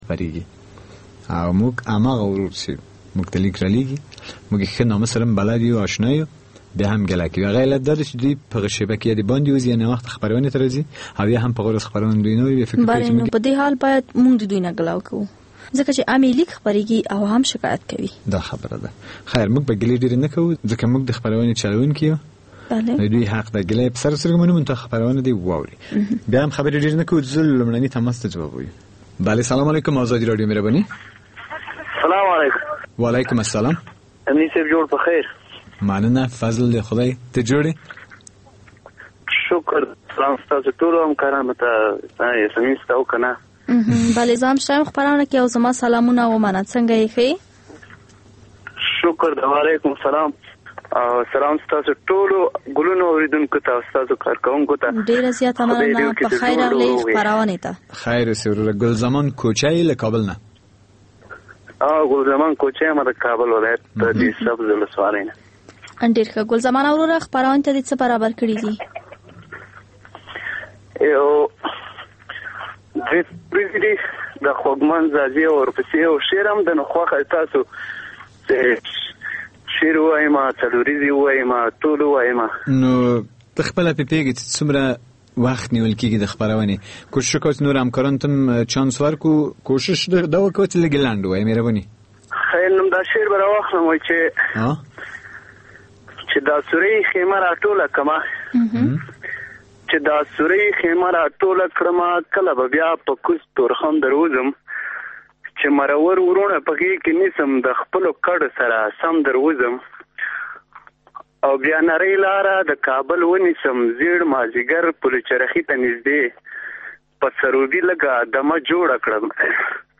د سندرو مېلمانه ( موسیقي)